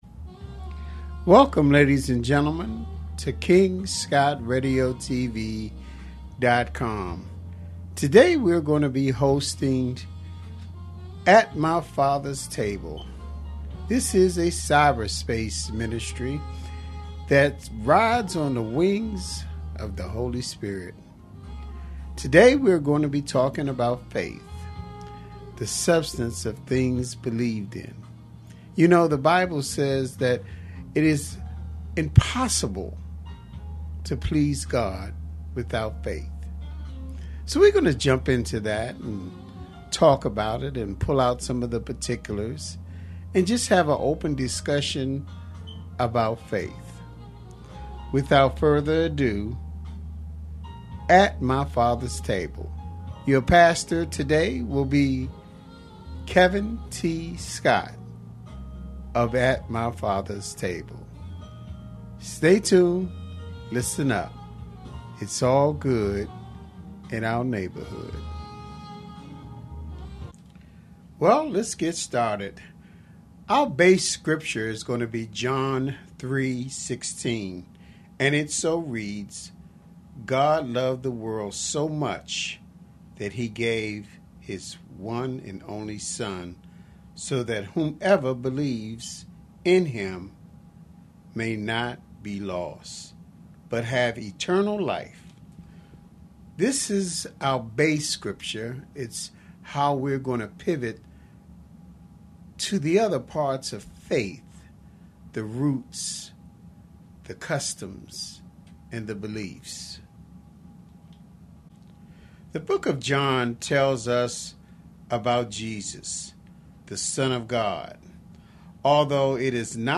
Bible Study for the New Year